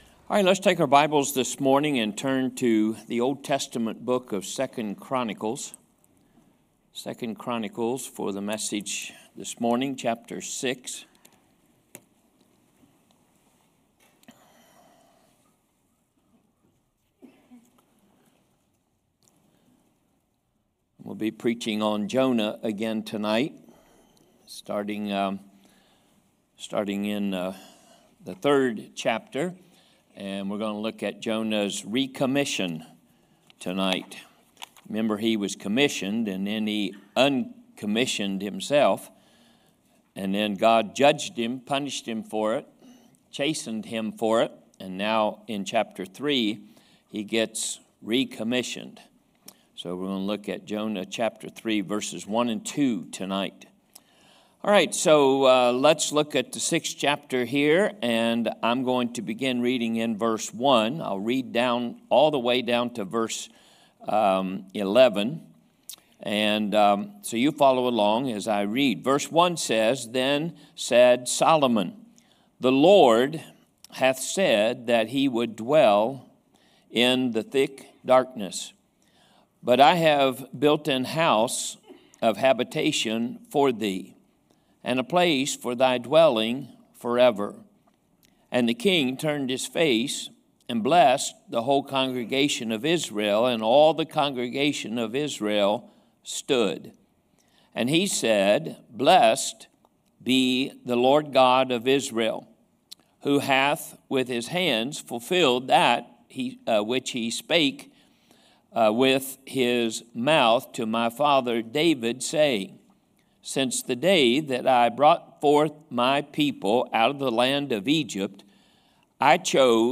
Sermon Notes from King Solomon